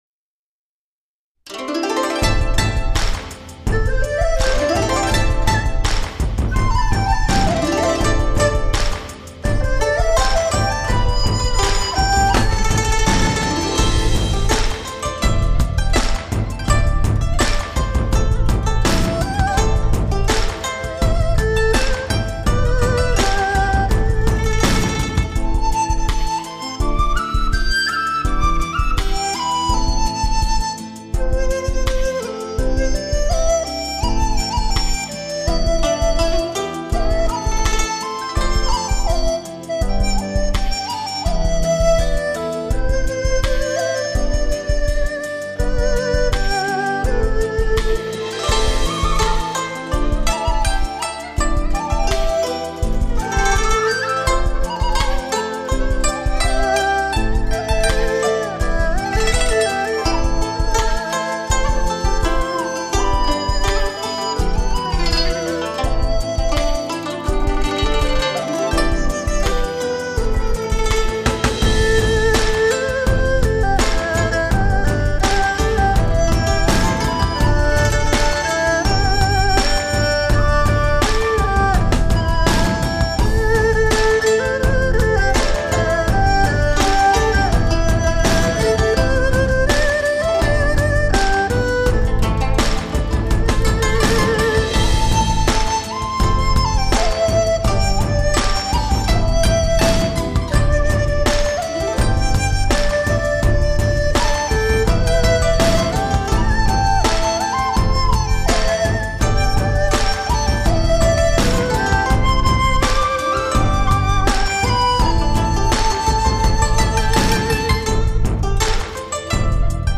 类型: 民乐雅韵
草原音乐之旅，悠扬的曲调，舒缓的旋律，辽阔的草原，古朴的风情，真